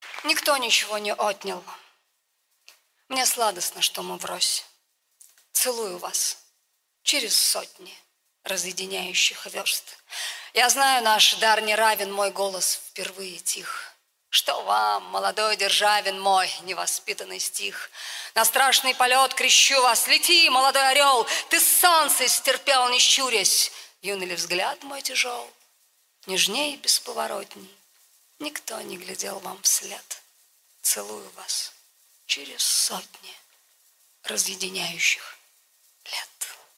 Cvetaeva-Nikto-nichego-ne-otnyal-chitaet-S.Surganova-stih-club-ru.mp3